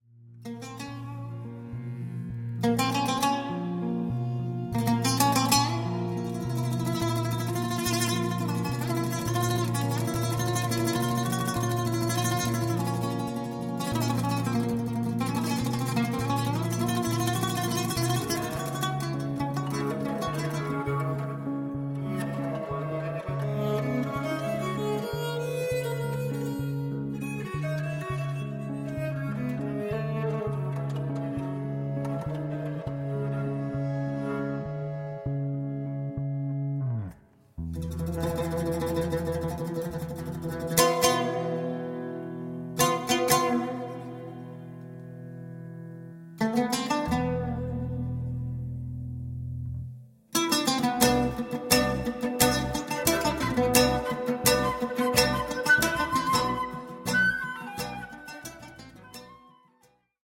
and sometimes warm, cheerful and magical
a real must-have for all Ethno Jazz enthusiasts.
WORLD